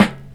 Rose Tinted Cheeks Snare.wav